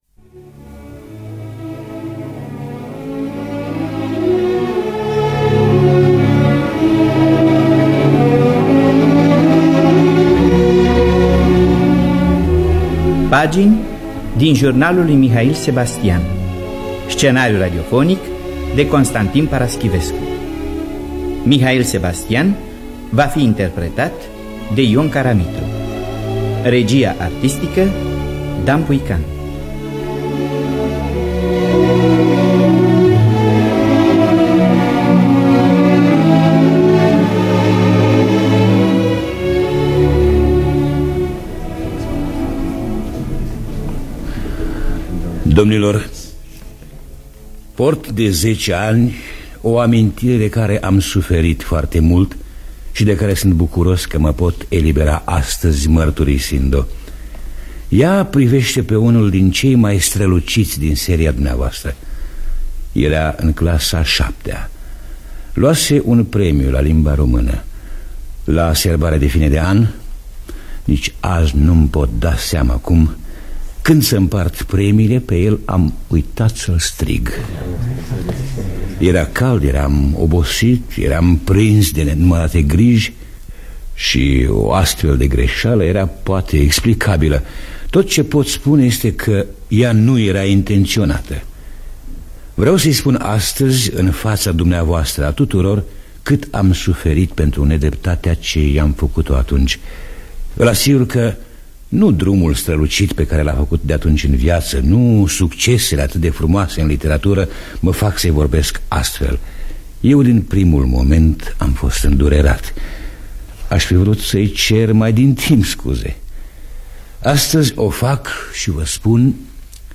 Conține fragmente din Fonoteca Teatrului Radiofonic.